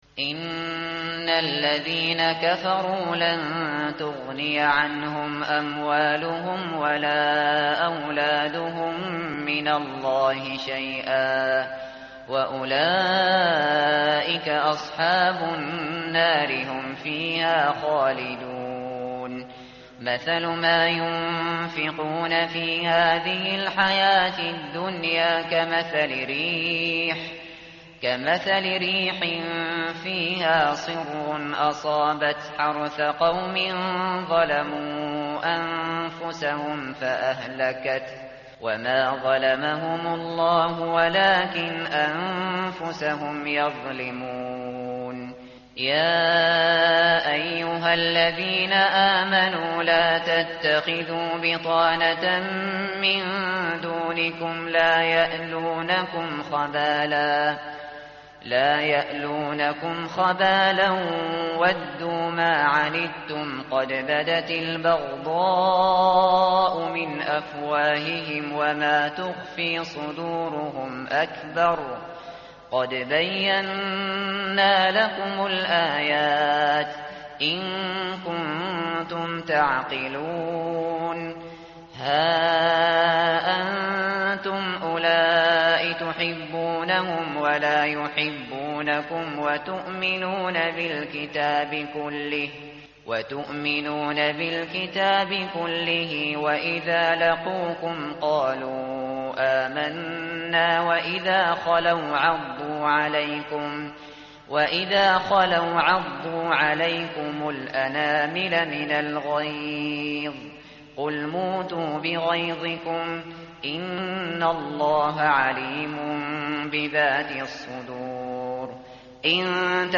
tartil_shateri_page_065.mp3